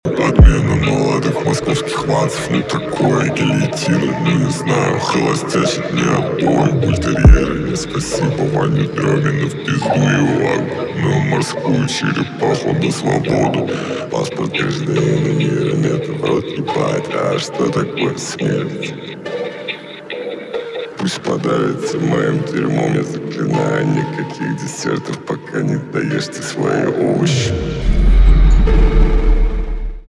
русский рэп
жесткие , битовые , басы , пугающие , страшные